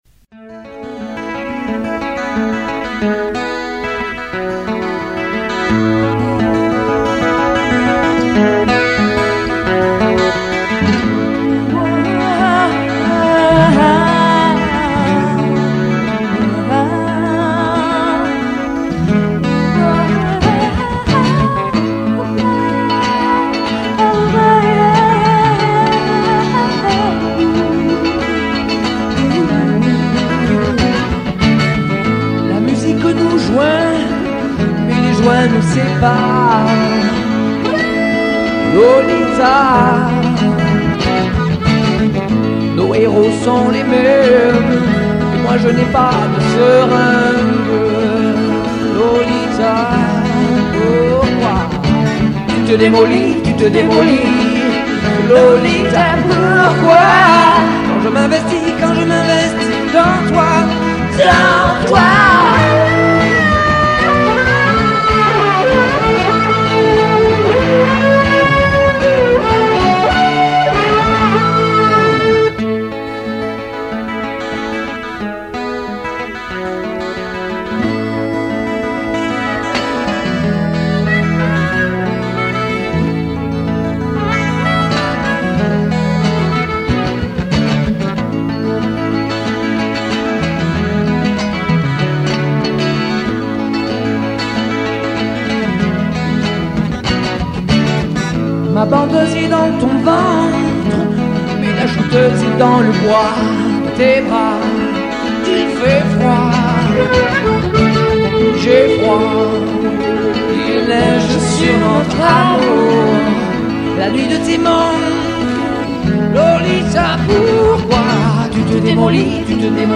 guit.dobro.chants
choeurs extraits